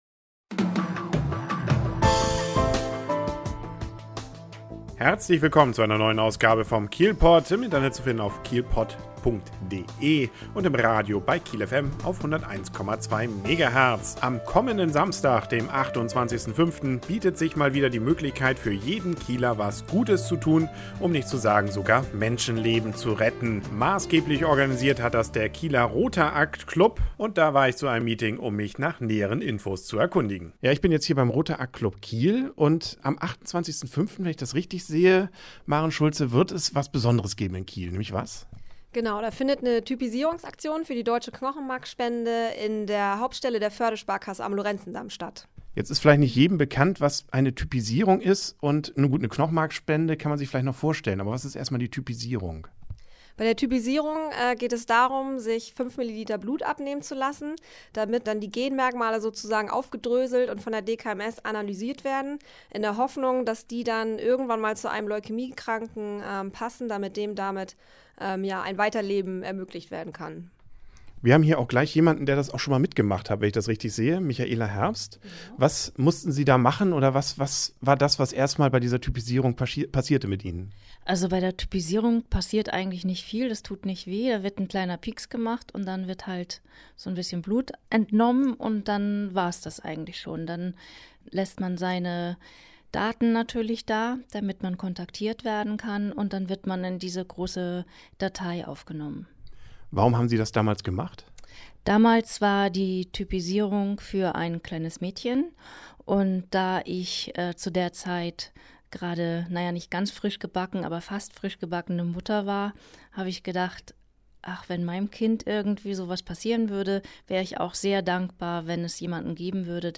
Hierzu habe ich mit einigen Organisatoren gesprochen. Aber auch eine Teilnehmerin, mit deren Spende einer Frau das Leben gerettet werden konnte, kommt zu Wort.